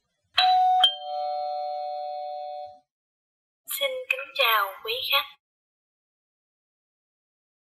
Âm thanh Chuông Báo Xin Kính Chào Quý Khách!
Thể loại: Tiếng chuông, còi
Description: Tải âm thanh chuông báo “Xin kính chào quý khách!” là lựa chọn tiện ích cho các cửa hàng, khách sạn, siêu thị hay văn phòng. Đây là file mp3 ghi âm lời chào tiếng Việt rõ ràng, thân thiện, dễ nghe, dùng cho các thiết bị chuông báo khách hoặc loa thông báo tự động.
am-thanh-chuong-bao-xin-kinh-chao-quy-khach-www_tiengdong_com.mp3